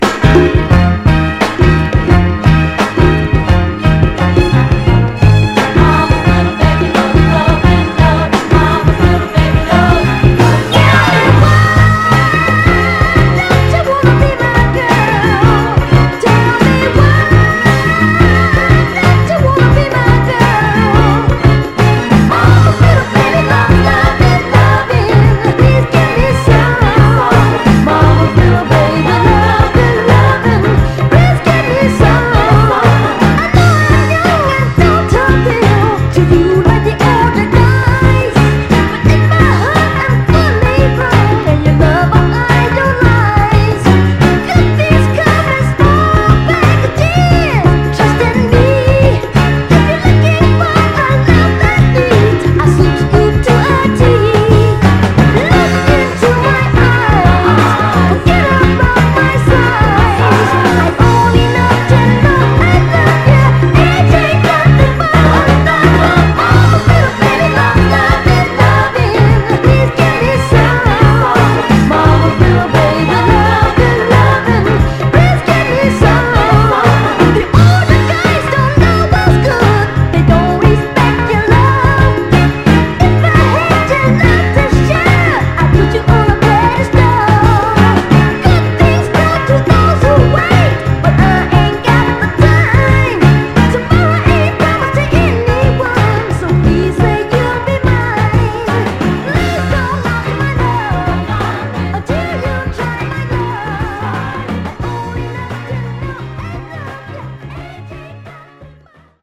盤は全体的に細かいスレ、細かい線キズ箇所あり。ただし音への影響は少なくプレイ概ね良好です。
※試聴音源は実際にお送りする商品から録音したものです※